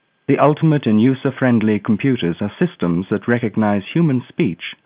The demo below contains a sample of a male voice originally sampled at 8kHz with 16 bit samples.
8 bit mu-law (nonlinear) this is the digital telephony standard